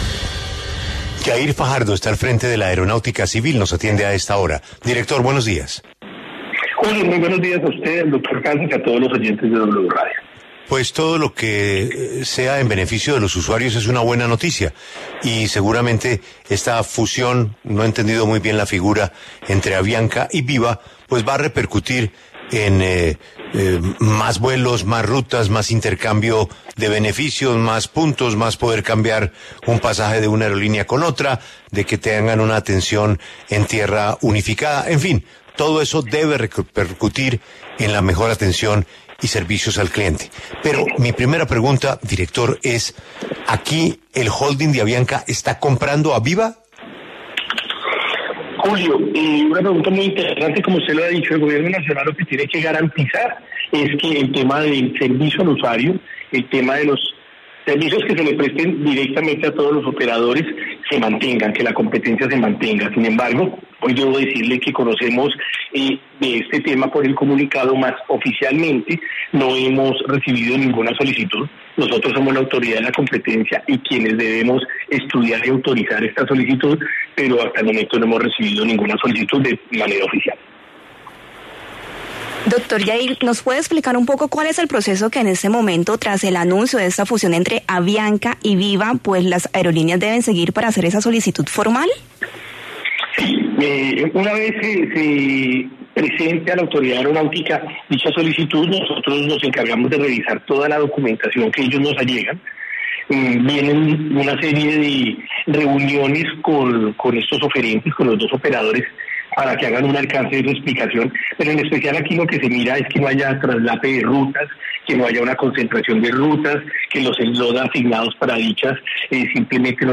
En diálogo con La W, Jair Orlando Fajardo, director de la Aeronáutica Civil, señaló que, hasta el momento, ni Avianca ni Viva Air han solicitado formalmente los permisos para la alianza que recientemente fue anunciada por ambas aerolíneas.